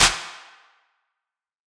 Clap Groovin 2.wav